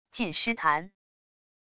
近失弹wav音频